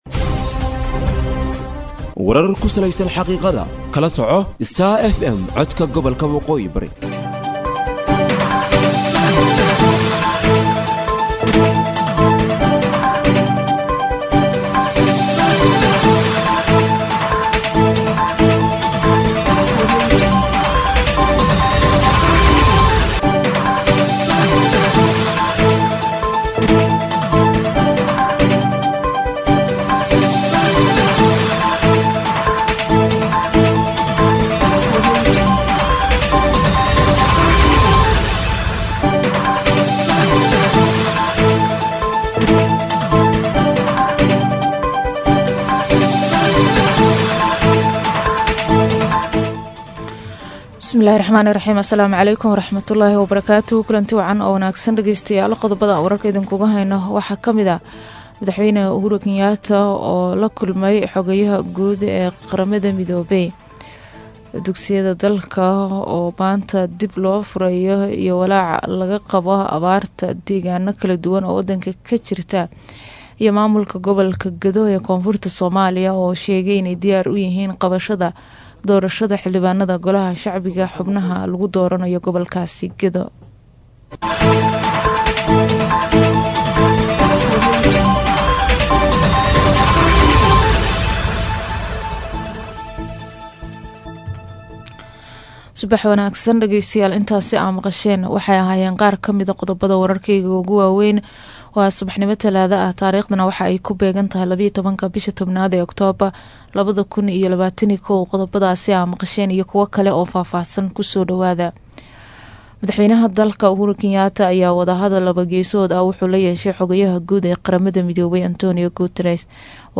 DHAGEYSO:WARKA SUBAXNIMO EE IDAACADDA STAR FM